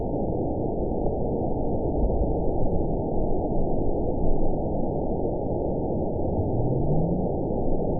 event 919975 date 02/02/24 time 05:34:18 GMT (1 year, 10 months ago) score 9.69 location TSS-AB07 detected by nrw target species NRW annotations +NRW Spectrogram: Frequency (kHz) vs. Time (s) audio not available .wav